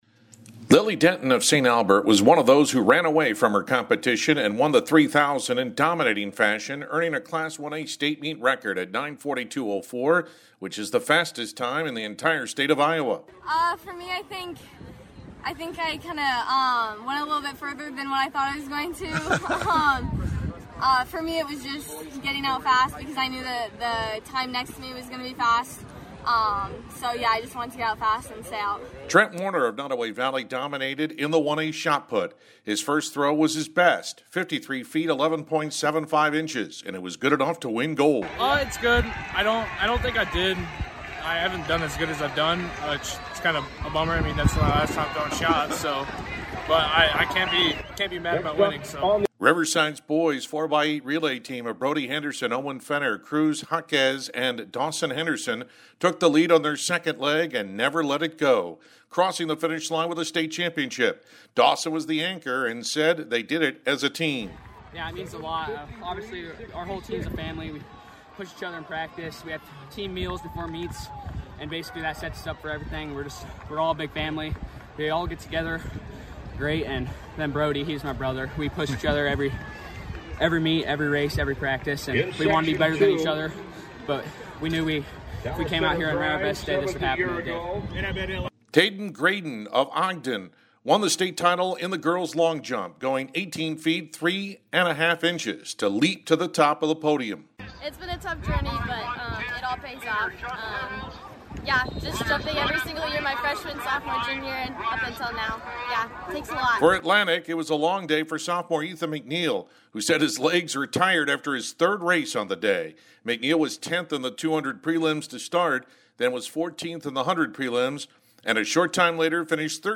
Day One Audio Recap